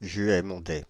Juaye-Mondaye (French pronunciation: [ʒɥɛ mɔ̃dɛ]
Fr-Juaye-Mondaye.ogg.mp3